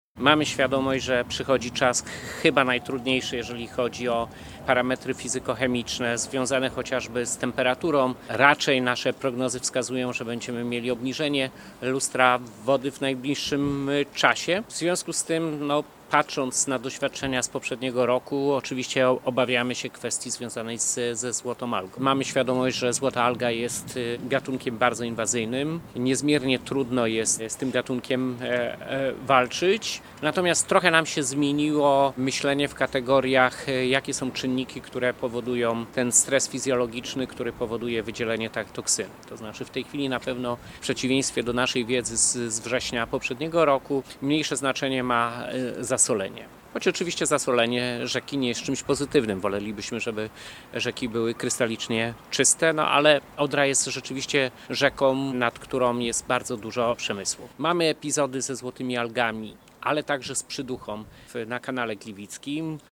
-Na tę chwilę nie mamy martwych ryb w głównym nurcie Odry. Przygotowujemy się do sezonu letniego, by uniknąć sytuacji sprzed roku – mówił w trakcie konferencji Jarosław Obremski – Wojewoda Dolnośląski nt. sytuacji rzeki.
-Przygotowujemy się do sezonu letniego, by uniknąć sytuacji sprzed roku, mówi wojewoda Jarosław Obremski.